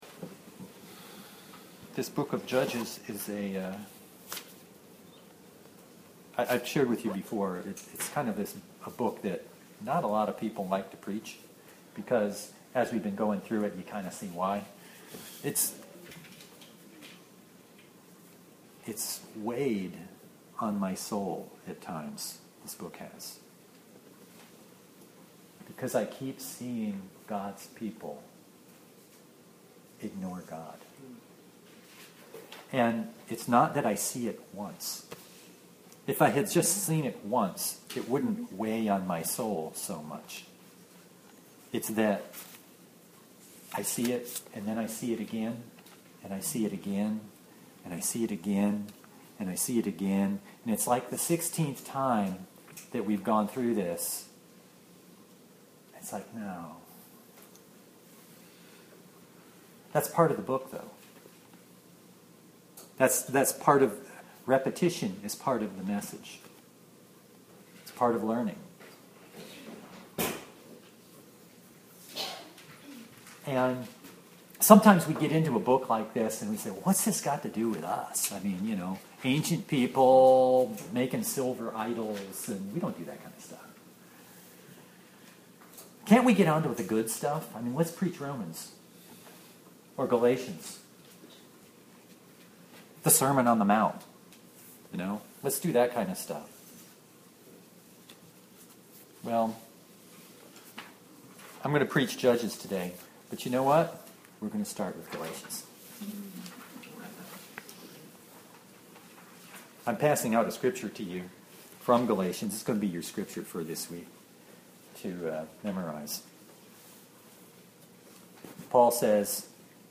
Passage: Judges 18:1-31 Service Type: Sunday Bible Text